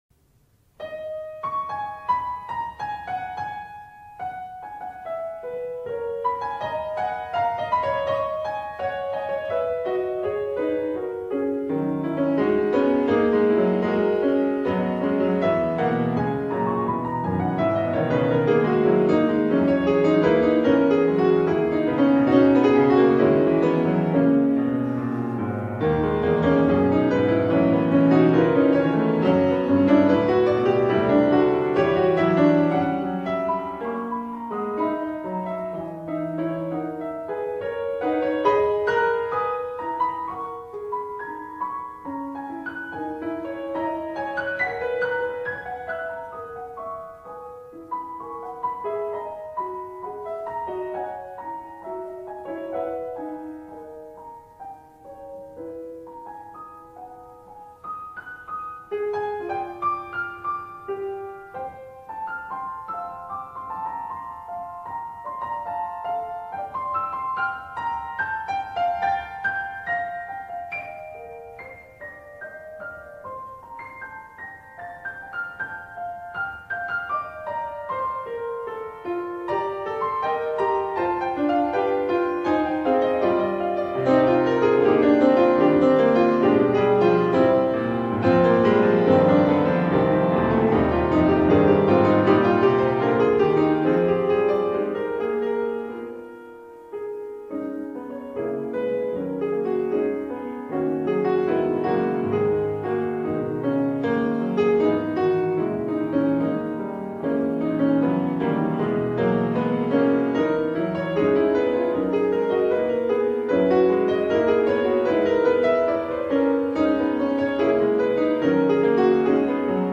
for a recital in the University of Missouri at Columbia’s Whitmore Hall in April of 2004